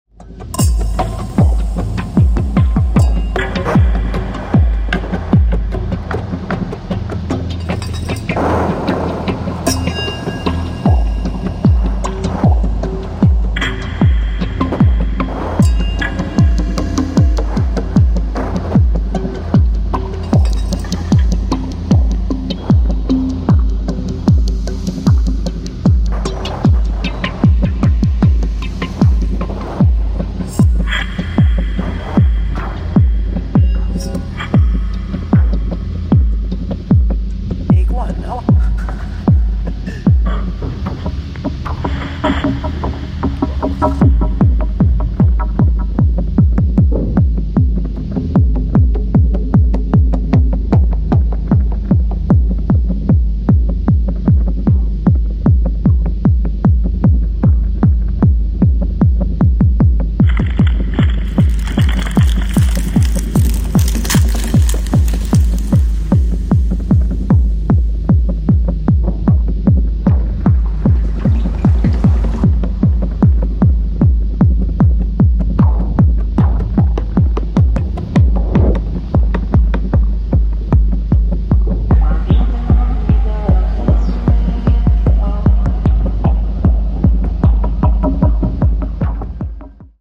4/4キックが登場するまでに3分以上掛けてじっくりと儀礼的空間への没入を促す9分に渡るオープナー